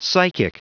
Prononciation du mot psychic en anglais (fichier audio)
Prononciation du mot : psychic